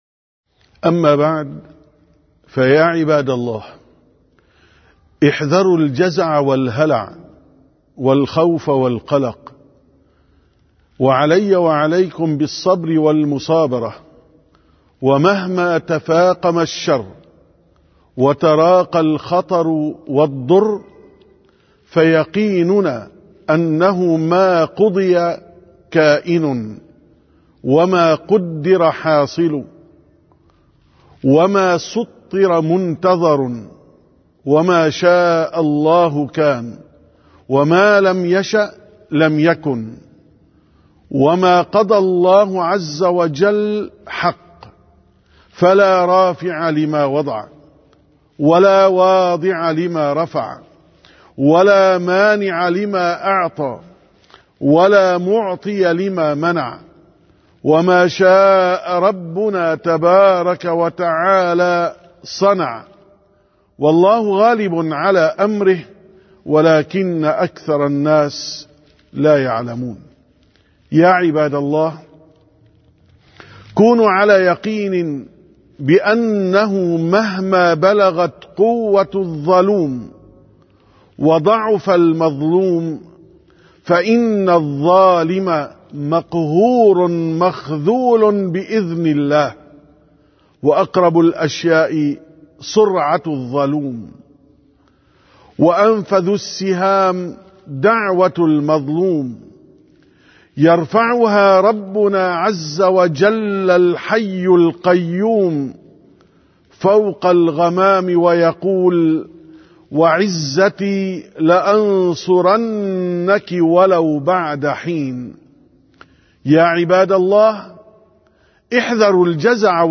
701ـ خطبة الجمعة: من مثلنا في الخلق؟